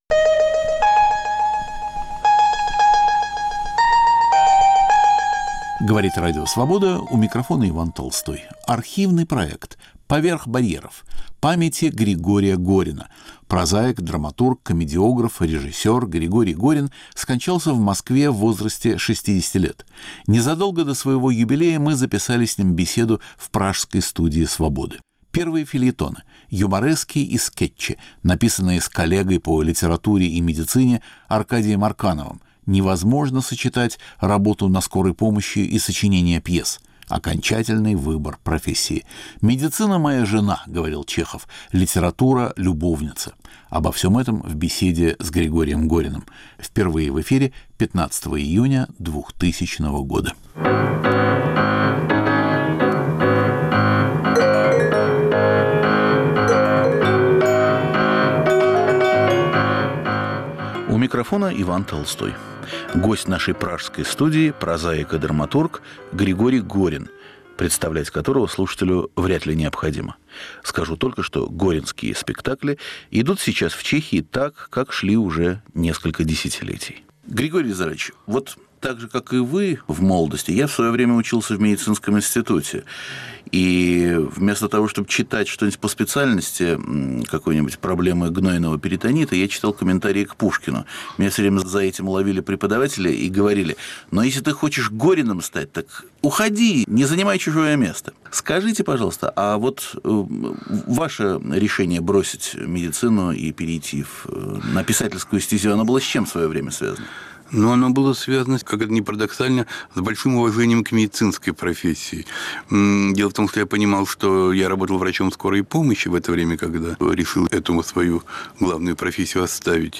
Архивный проект. Иван Толстой выбирает из нашего эфира по-прежнему актуальное и оказавшееся вечным.